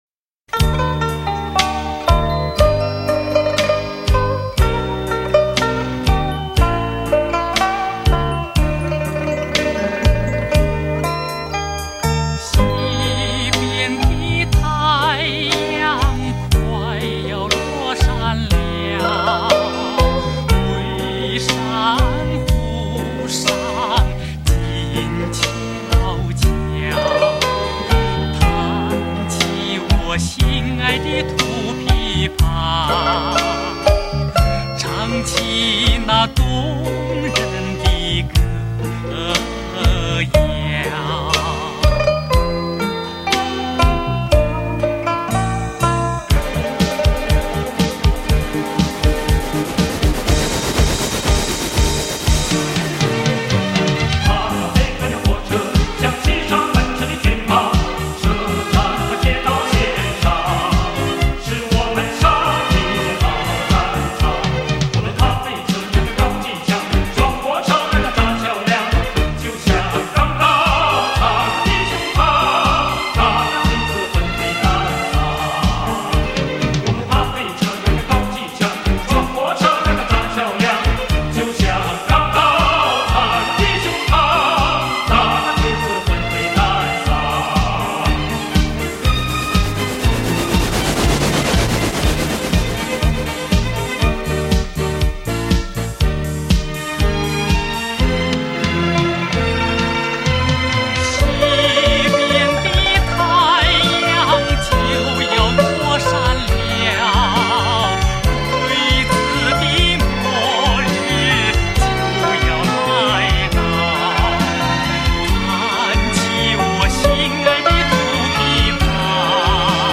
音质：正版CD转320K/MP3